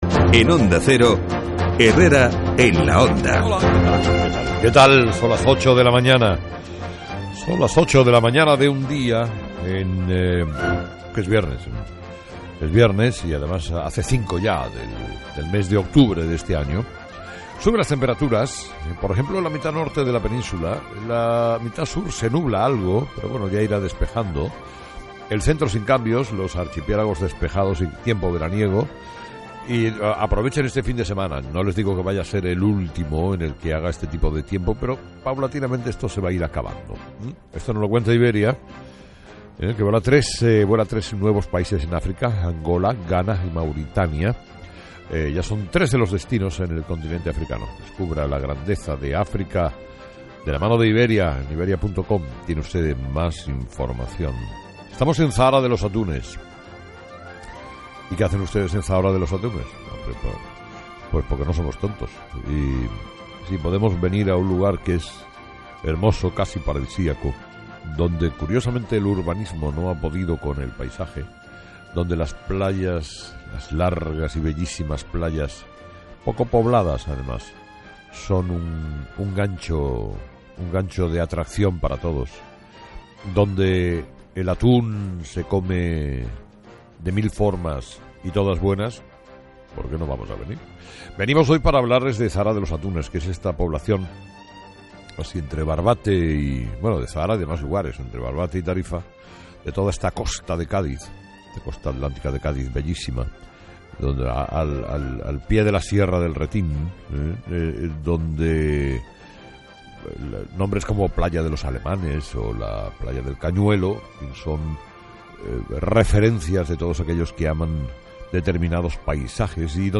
05/10/2012 Editorial de Carlos Herrera: 'El auto de Santiago Pedraz es discutible técnicamente'